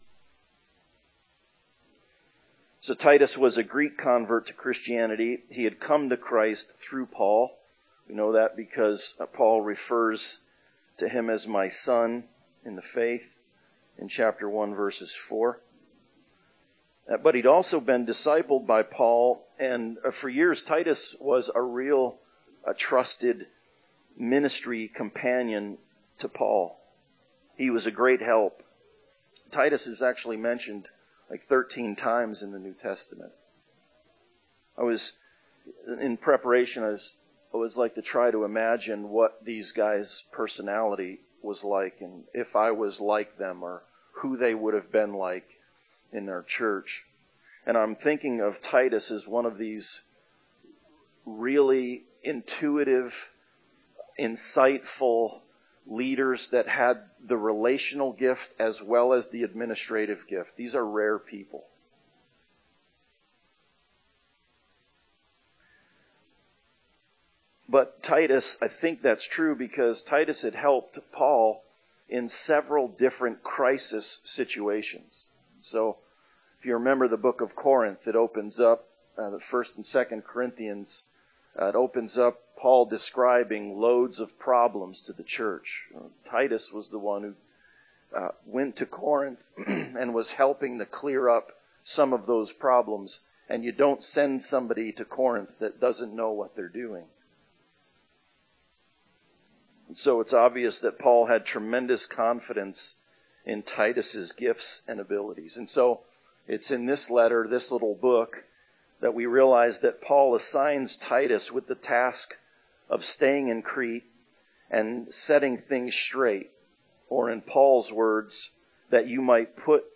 Passage: Titus 1:1-4 Service Type: Sunday Service